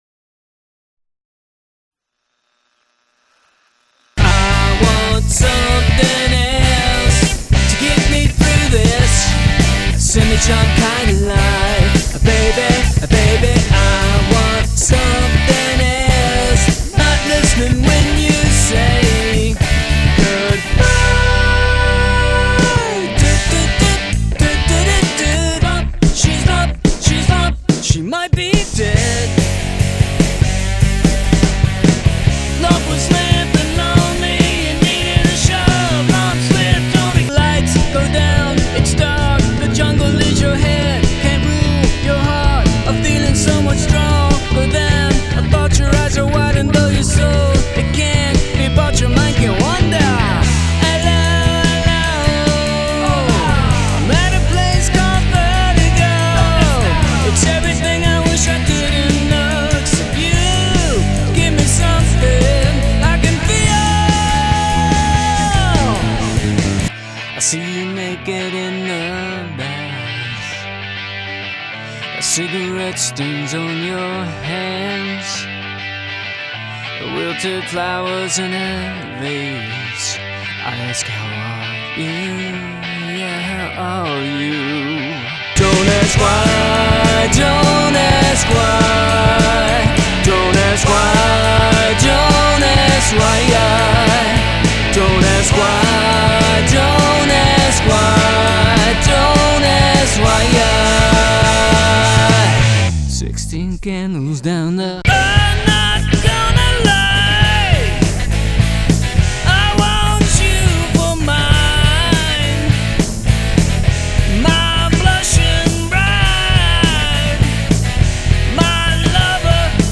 a fun, uptempo four-piece band